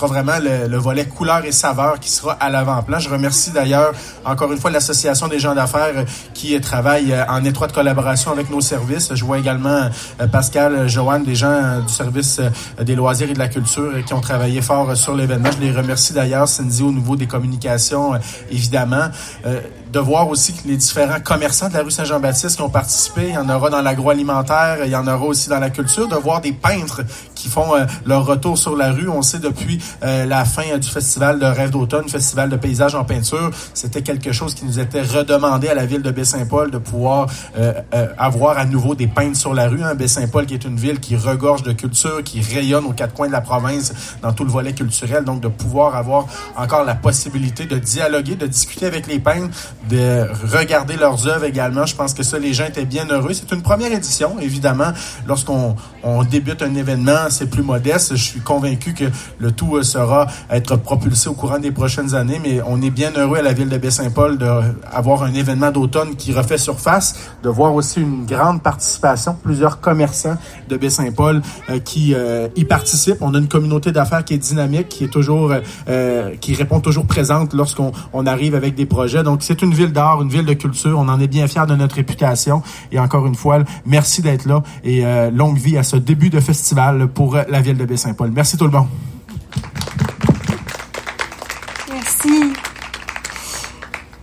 Le maire de Baie-Saint-Paul, Michaël Pilote.